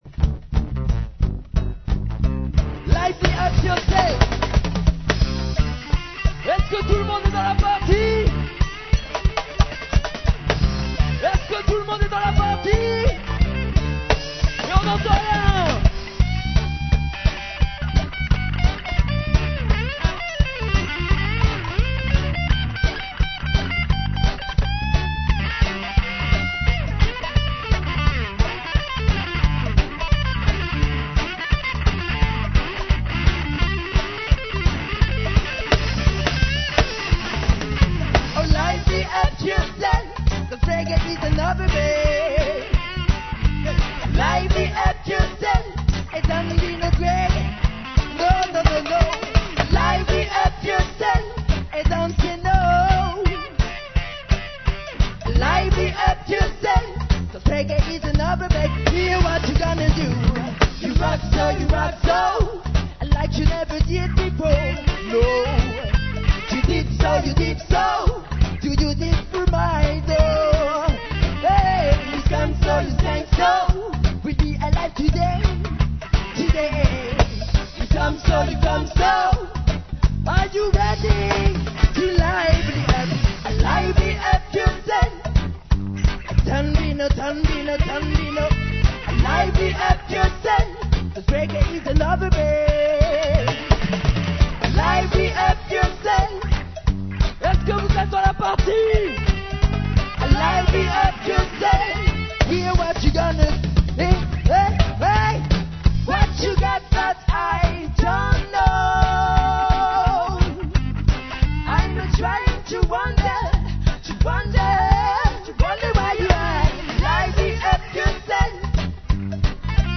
CD démo 5 titres Live ! (2000)
Enregistré live à la Lune des Pirates